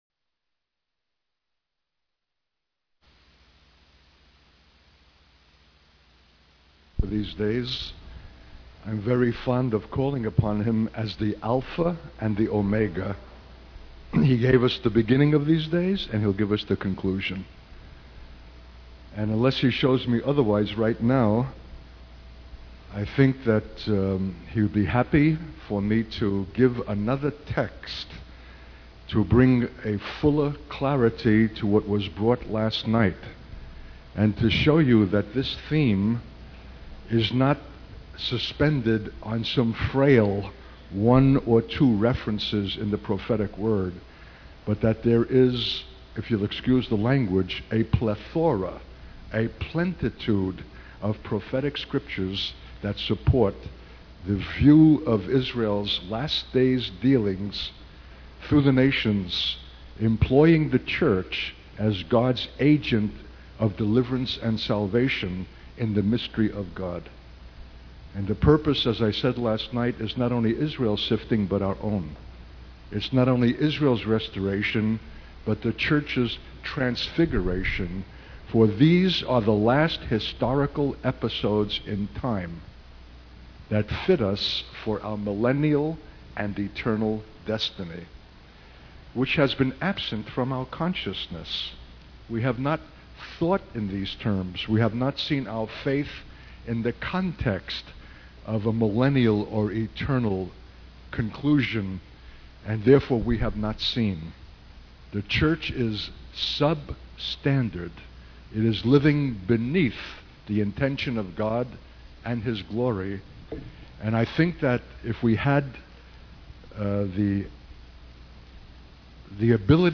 In this sermon, the speaker reflects on a personal experience of feeling melancholy and alone while carrying the burden of understanding the mystery of Israel. He emphasizes the importance of simplicity and clarity in understanding the word of God, rather than seeking esoteric or sensational interpretations. The speaker calls for a commitment to God's plan, even if it is difficult to comprehend or imagine.